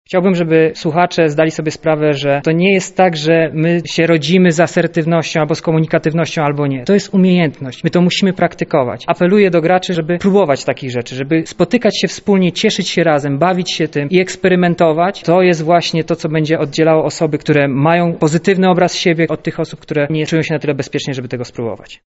mówi psycholog